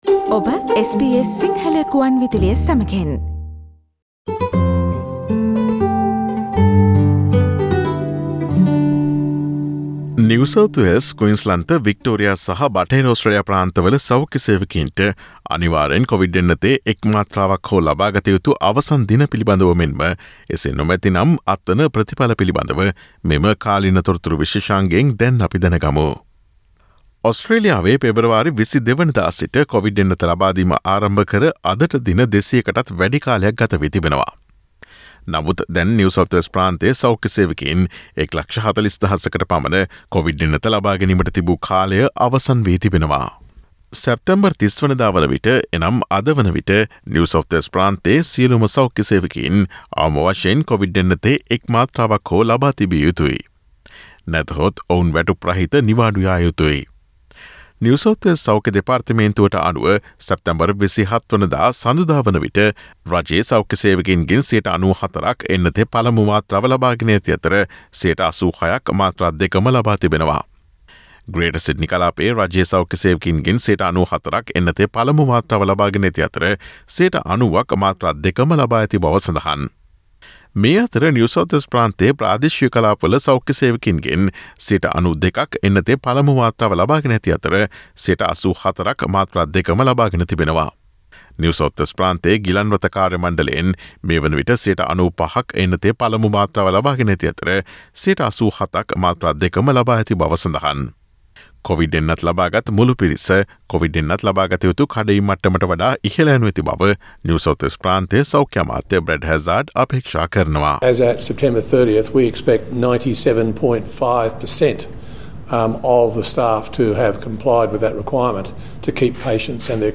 ඔස්ට්‍රේලියාවේ නිව් සවුත් වේල්ස්, ක්වීන්ස්ලන්ත, වික්ටෝරියා සහ බටහිර ඔස්ට්‍රේලියා ප්‍රාන්තවල සෞඛ්‍ය සේවකයින්ට අනිවාර්යෙන් කොවිඩ් එන්නතේ එක් මාත්‍රාවක් හෝ ලබාගත යුතු අවසන් දින පිළිබඳ මෙන්ම එසේ නොමැතිනම් අත්වන ප්‍රතිඵල පිළිබඳව නවතම තොරතුරු රැගත් සැප්තැම්බර් 30 වන දා බ්‍රහස්පතින්දා ප්‍රචාරය වූ SBS සිංහල සේවයේ කාලීන තොරතුරු විශේෂාංගයට සවන්දෙන්න.